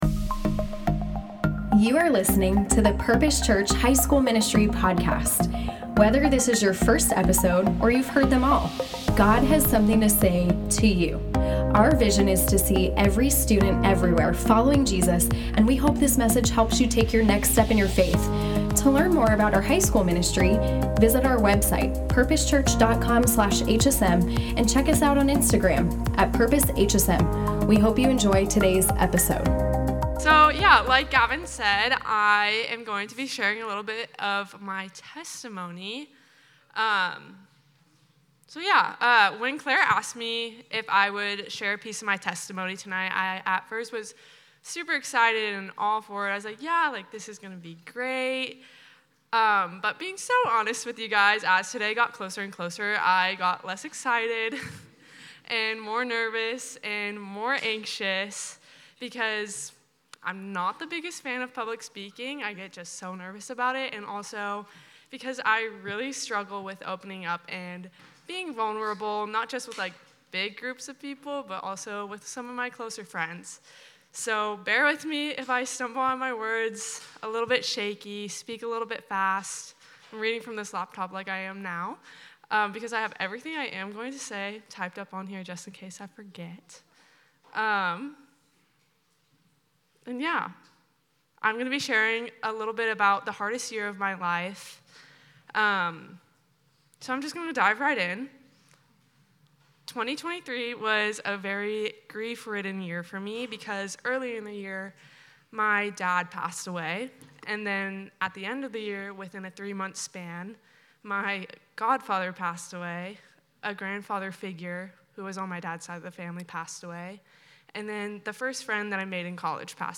Thanksgiving Feast